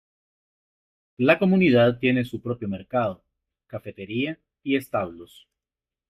Pronounced as (IPA) /esˈtablos/